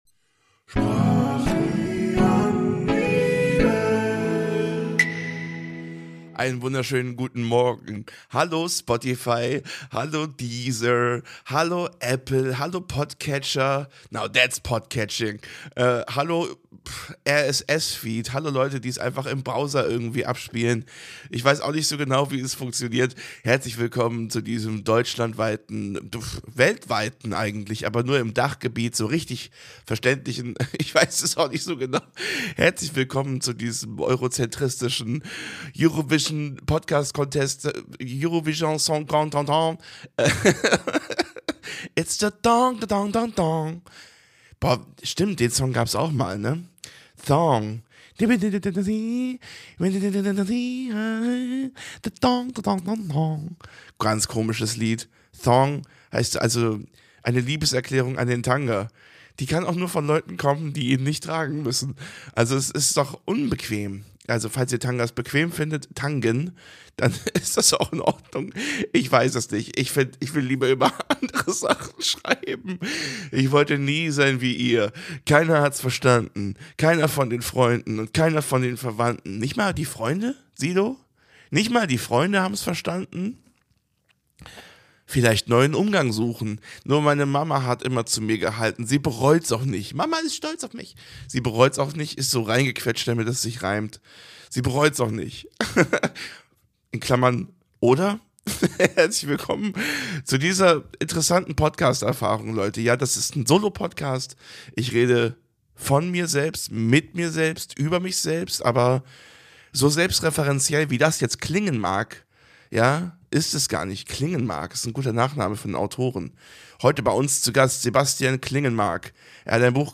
Das Leben hat nicht einen Sinn, es hat mindestens fünf. Gedanken aus dem Hotelzimmer in Leipzig.